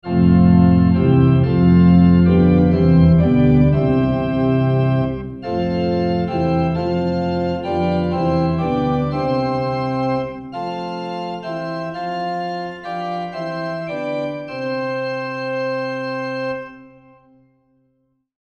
Probably most venues with pipe organs that I see have high BR’s and low TR’s, and have a characteristic similar to that shown in Figure 4.
2. The “typical” sound clip is “organ normal RT.wav”.
organ-normal-RT.wav